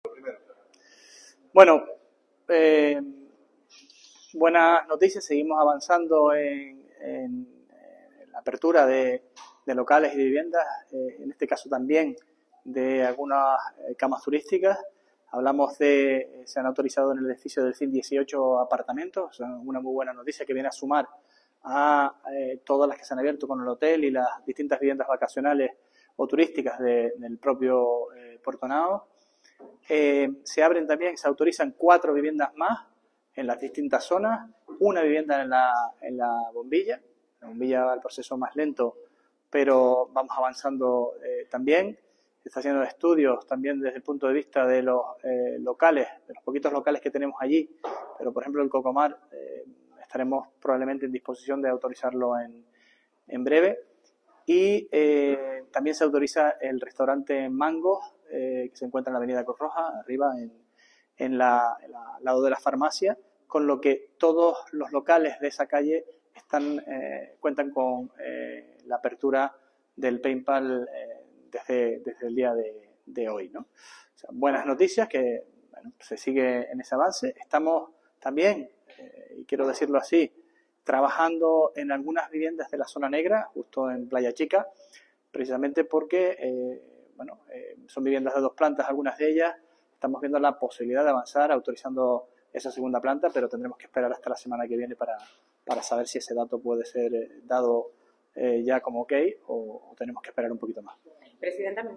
Declaraciones audio Sergio Rodríguez Peinpal.mp3